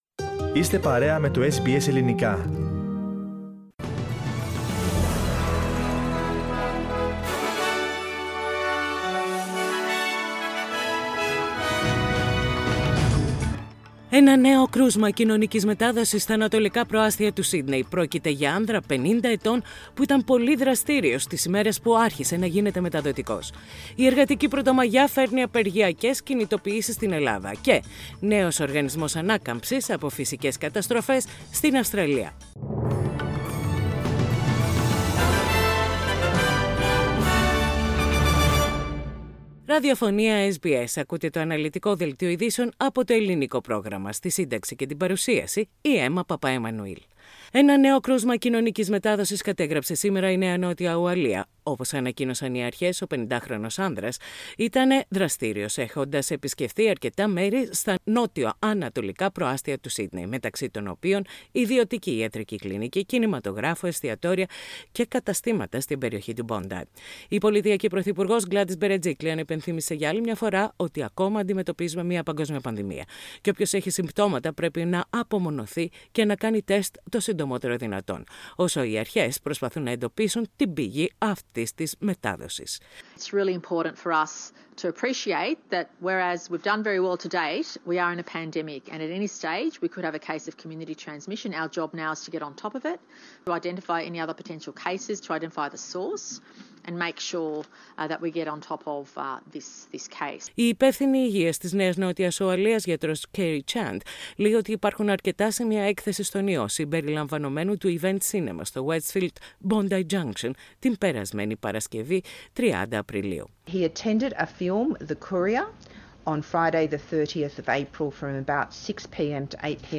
Main news of the day from SBS radio Greek program.